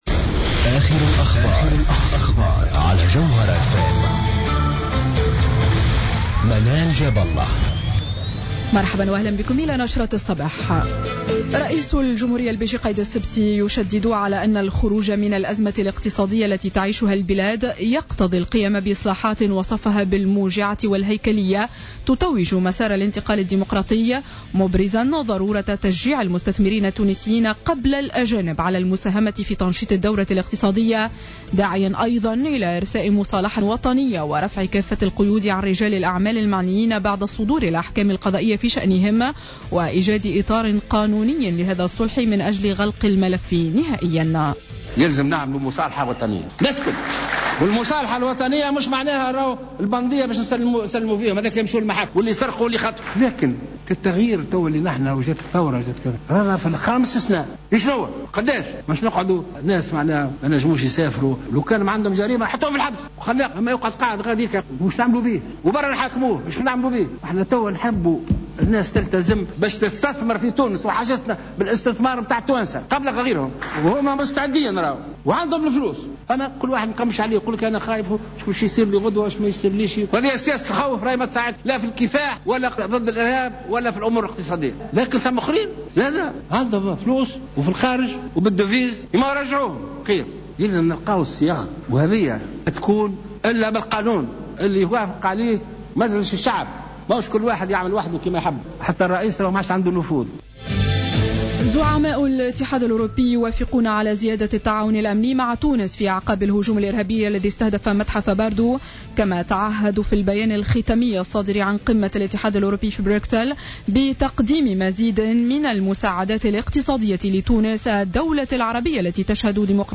نشرة أخبار السابعة صباحا ليوم السبت 21 مارس 2015